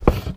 MISC Wood, Foot Scrape 02.wav